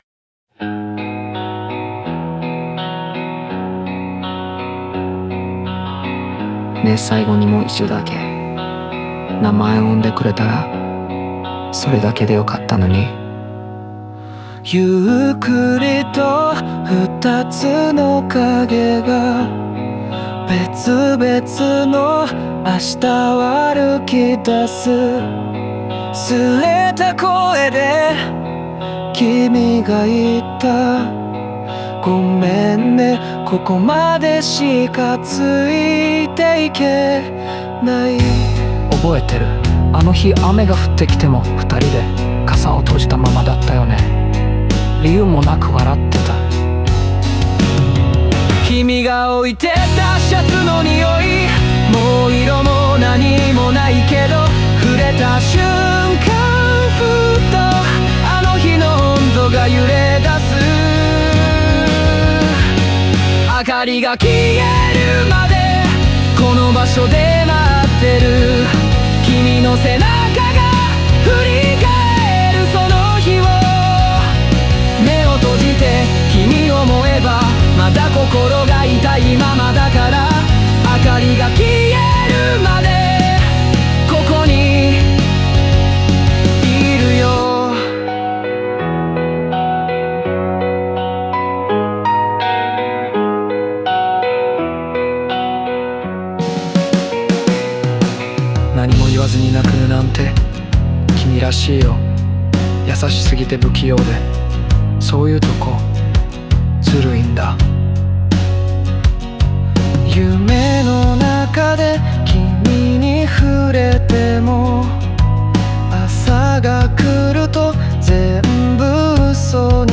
静と動を織り交ぜたバンドサウンドと哀愁漂うメロディが、感情の波を丁寧にすくい取る。